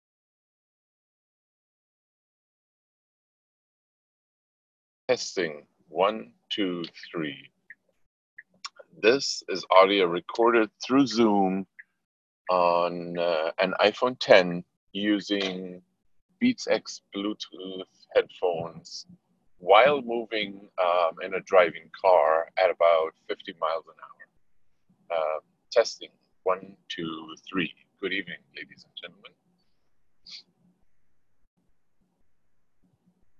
recording in car
beatsx-moving-car.m4a